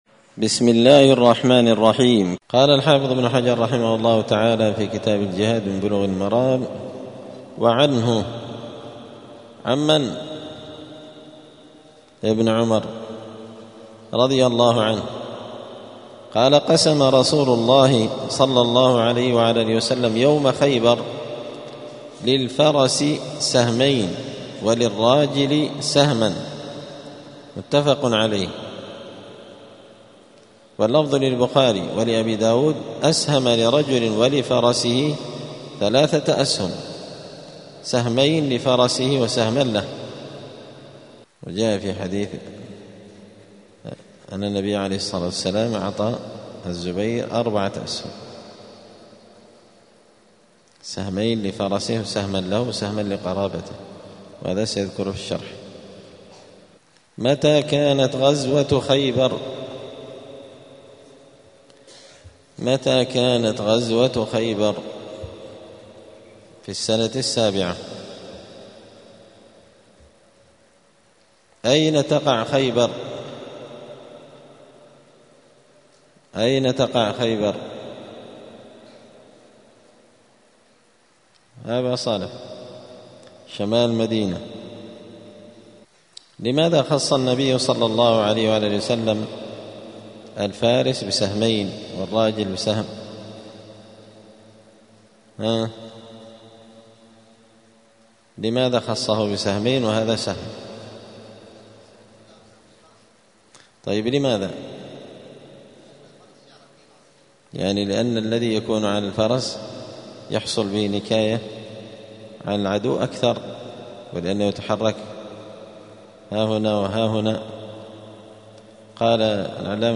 *الدرس العشرون (20) {باب ﺳﻬﻢ اﻟﻔﺎﺭﺱ ﻭاﻟﻔﺮﺱ ﻭاﻟﺮاﺟﻞ}*
دار الحديث السلفية بمسجد الفرقان قشن المهرة اليمن 📌الدروس اليومية